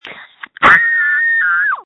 This is just a sample of the many screams recorded on January 20, 2021.
• When you call, we record you making sounds. Hopefully screaming.